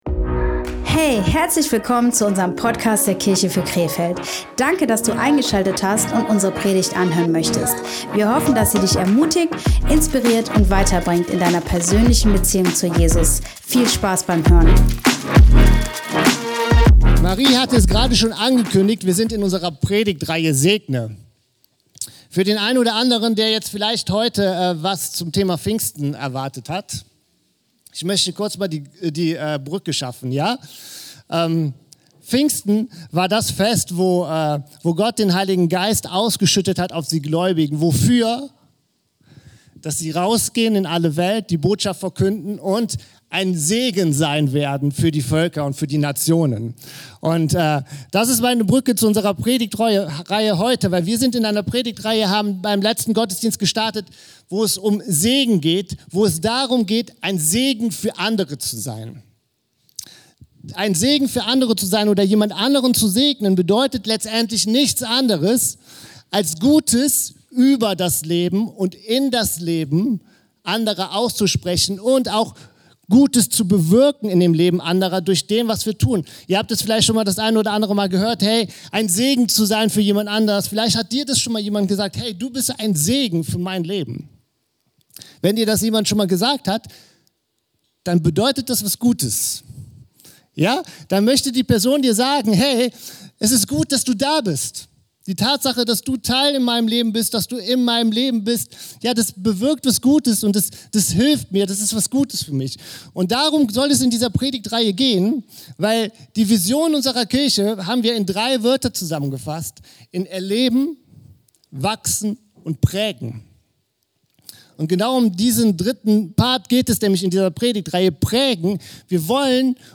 Im zweiten Teil unserer Predigtreihe beschäftigen wir uns mit der Wichtigkeit des Zuhörens. Wenn Du ein Segen im Leben von anderen Menschen sein möchtest, achte darauf, ihnen zuerst zuzuhören.